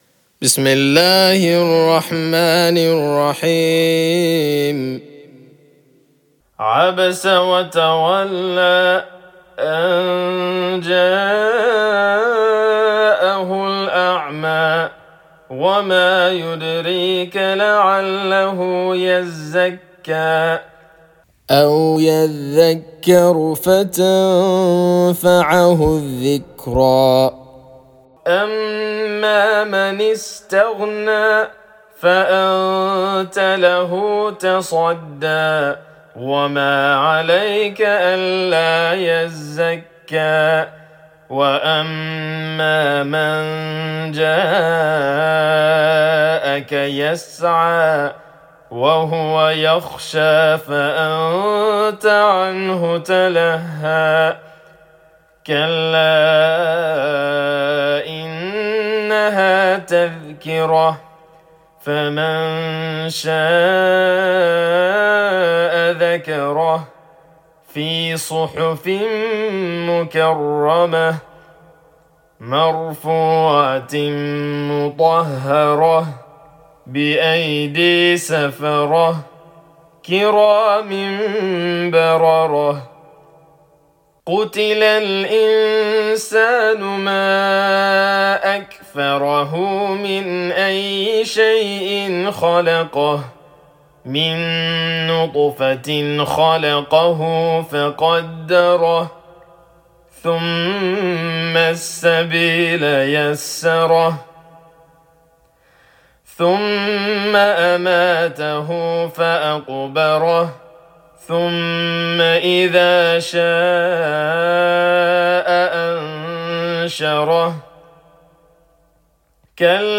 Chapter_80,_Abasa_(Murattal)_-_Recitation_of_the_Holy_Qur'an.mp3